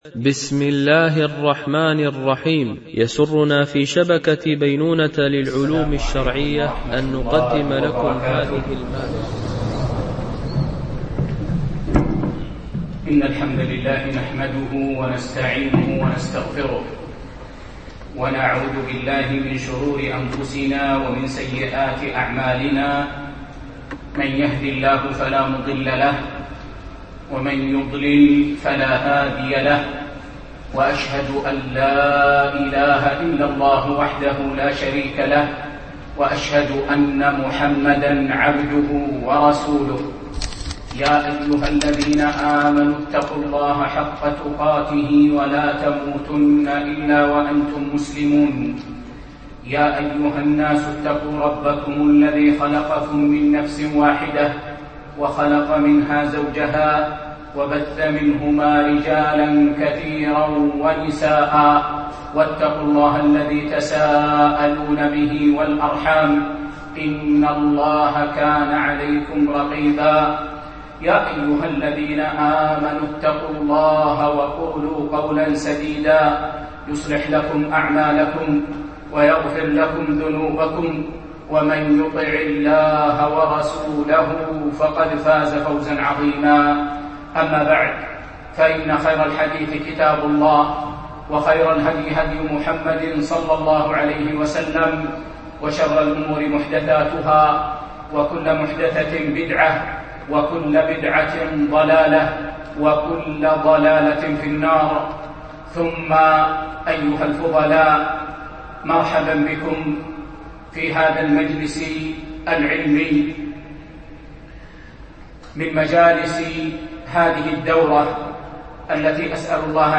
شرح كتاب الصيام من موطأ الإمام مالك ـ الدرس 6